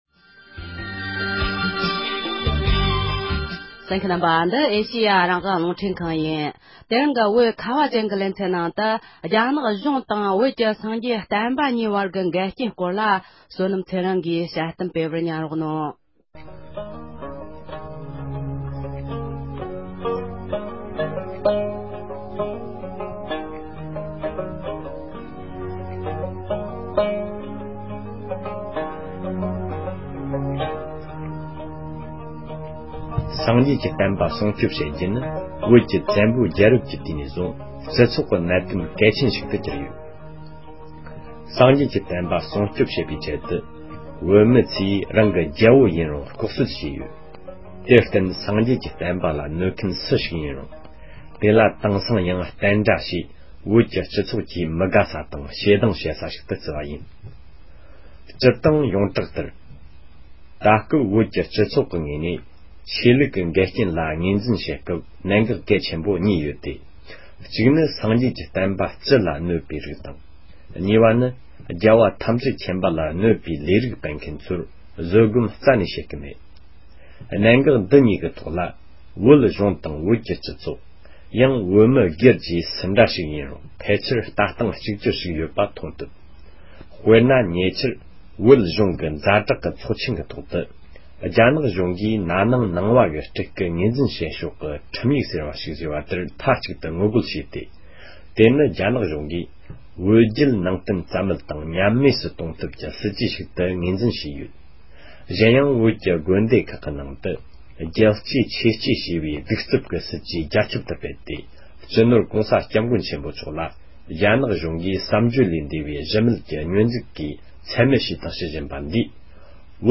ནག་གཞུང་དང་བོད་ཀྱི་སངས་རྒྱས་བསྟན་པ་གཉིས་བར་གྱི་འགལ་རྐྱེན་སྐོར་གྱི་དཔྱད་གཏམ།